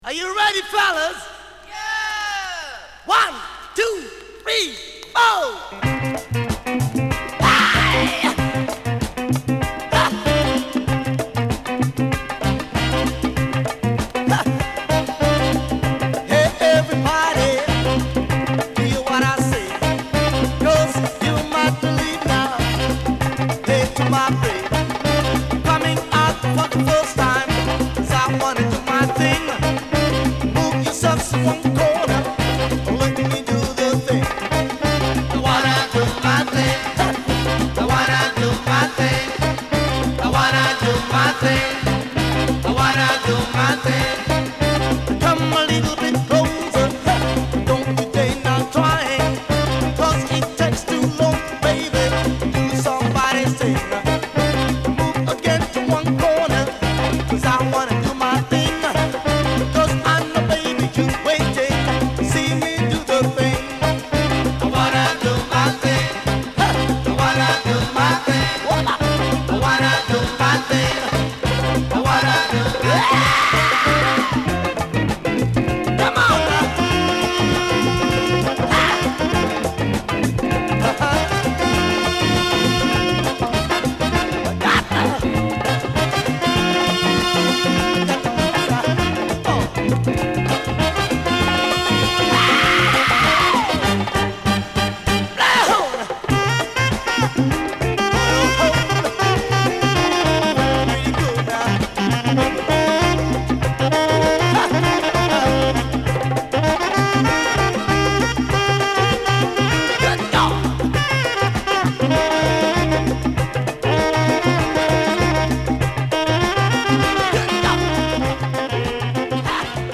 ケニアのアフロファンクバンド
＊SideB後半キズ／最後まで周回ノイズ出ます／SideA:VG+